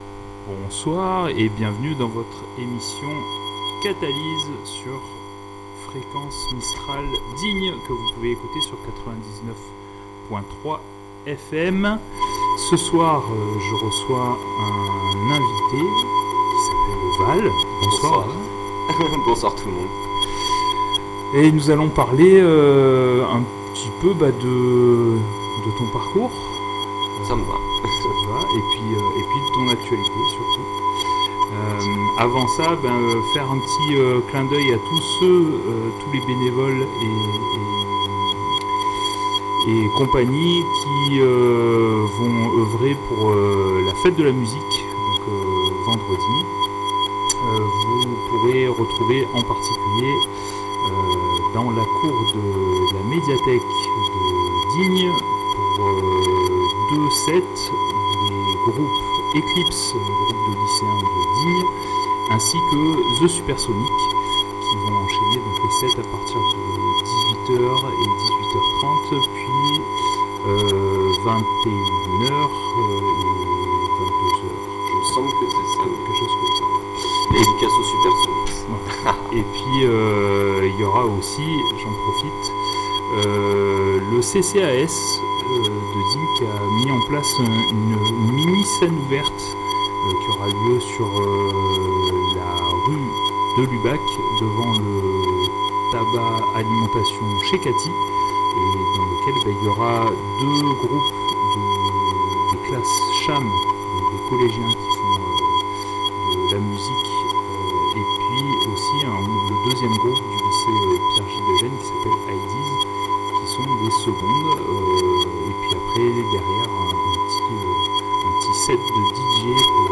(Dommage le son est endommagé les 20 premières minutes de l'émission (il aurait fallu mettre les casques pour écouter que le son n'était pas OK et virer le micro qui frisait le larsen) !)